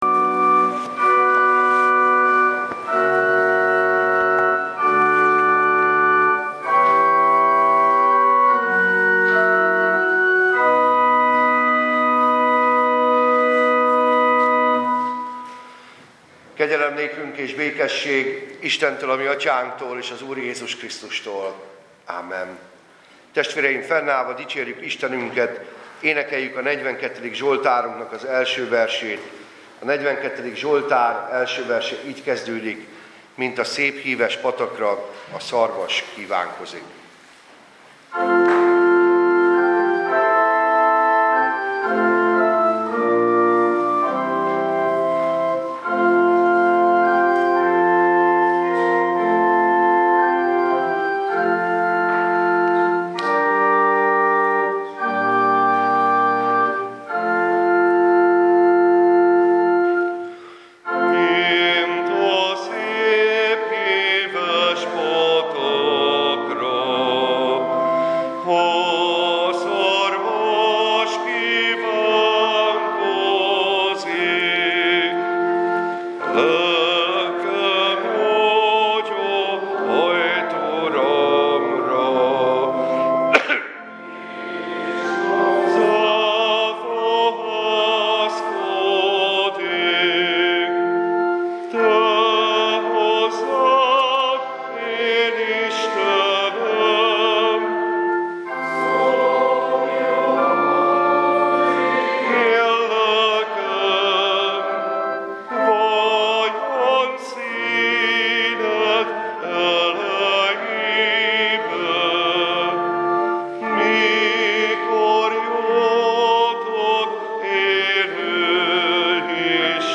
Passage: Pál levele a Galatákhoz 5, 1-6 Service Type: Igehirdetés